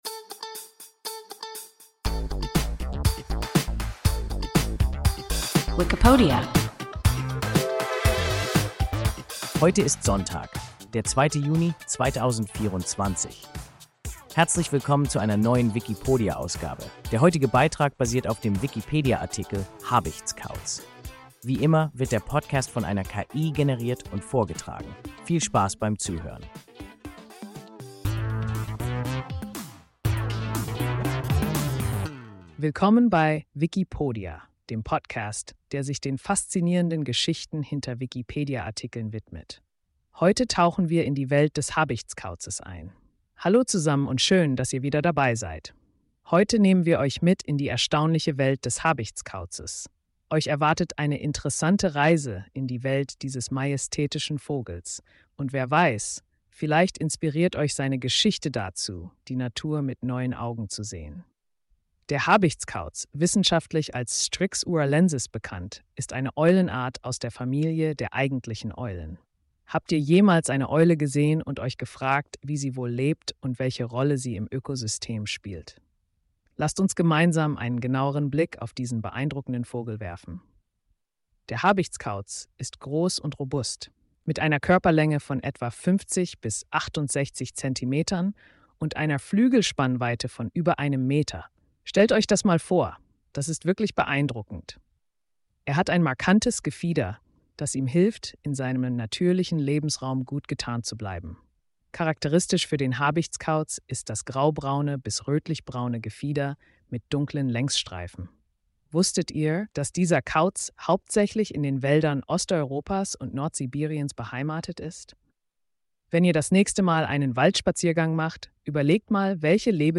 Habichtskauz – WIKIPODIA – ein KI Podcast